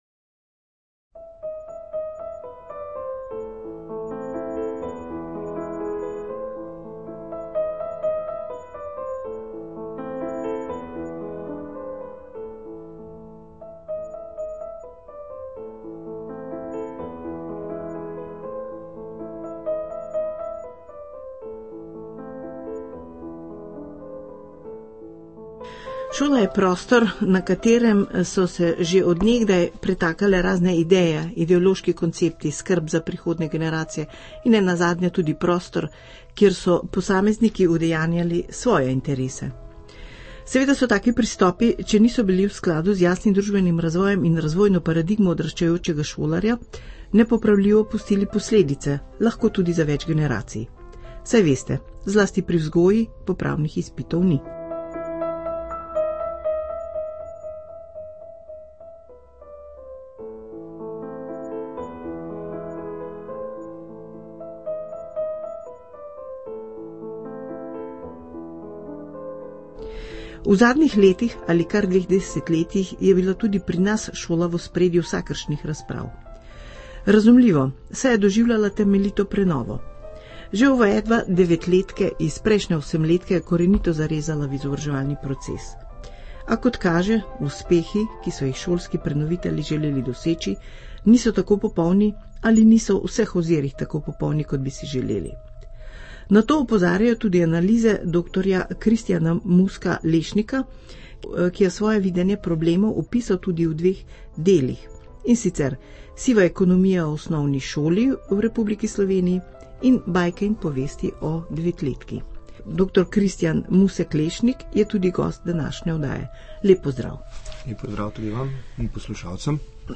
Intervju ARS.mp3